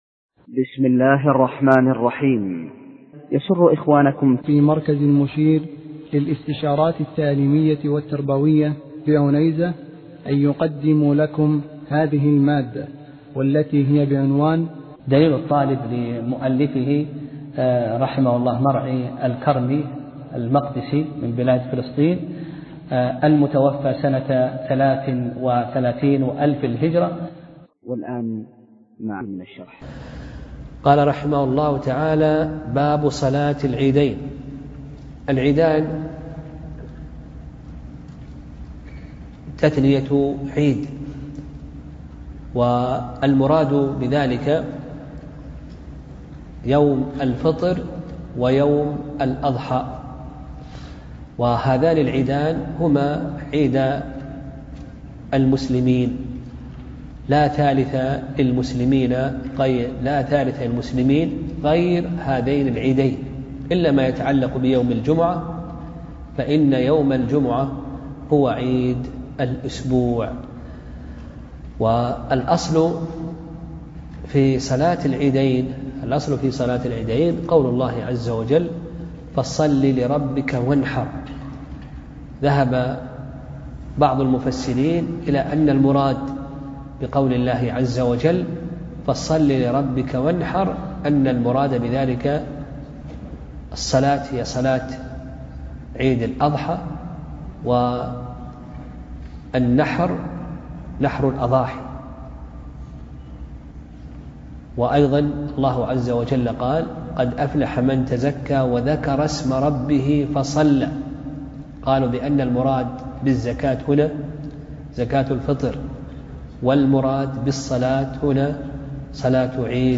درس (22) : باب صلاة العيدين